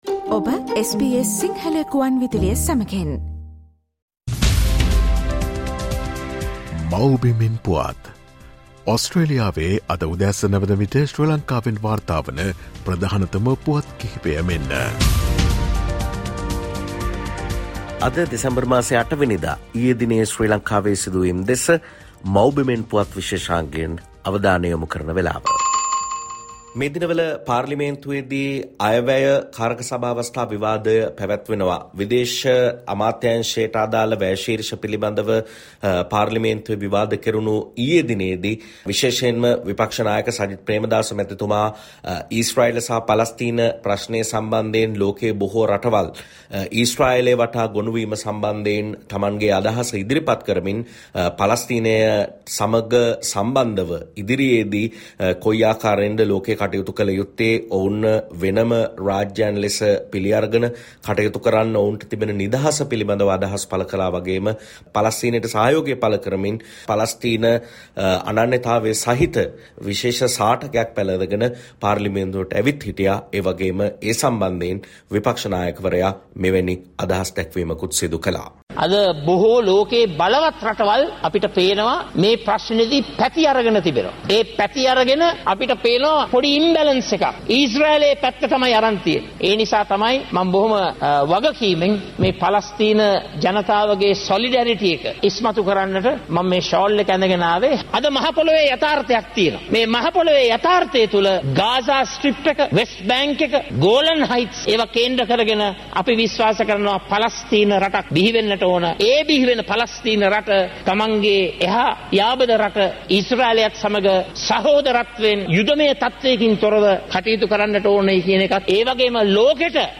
SBS Sinhala featuring the latest news reported from Sri Lanka